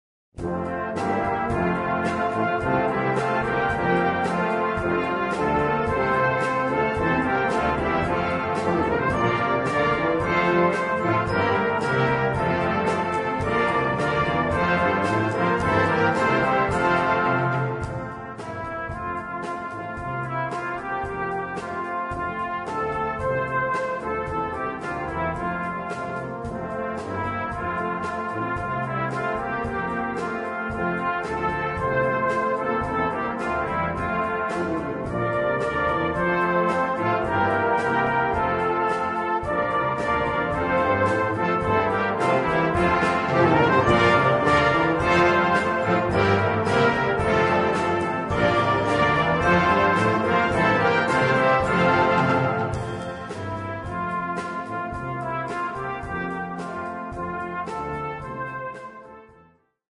Komponist: Traditionell
A4 Besetzung: Blasorchester Zu hören auf